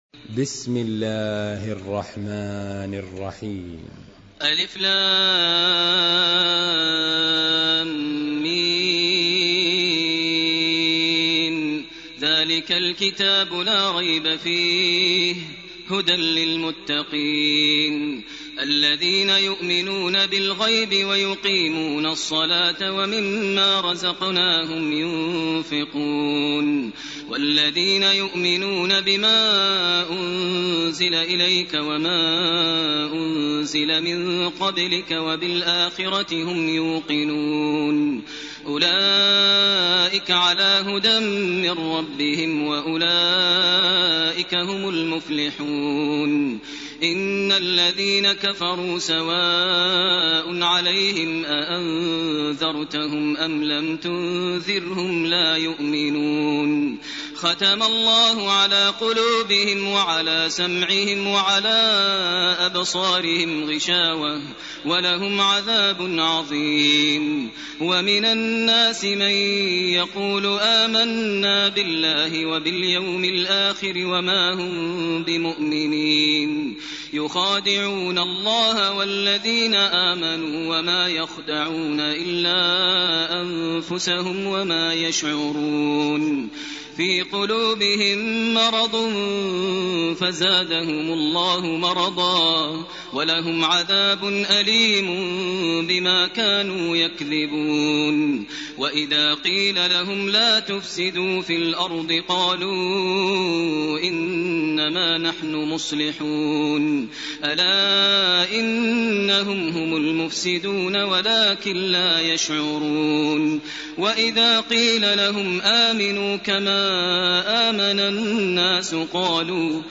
سورة البقرة 1-75 > تراويح ١٤٢٨ > التراويح - تلاوات ماهر المعيقلي